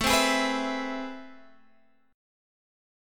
A Minor Major 9th